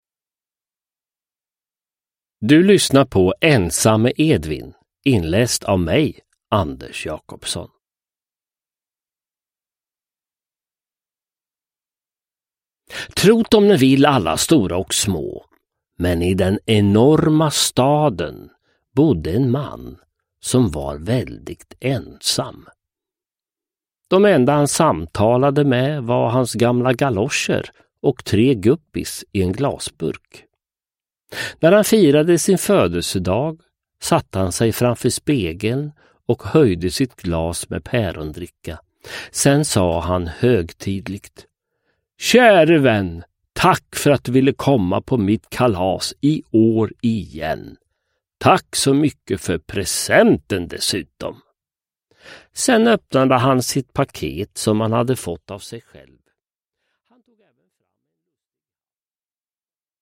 Ensamme Edvin – Ljudbok – Laddas ner
Uppläsare: Sören Olsson, Anders Jacobsson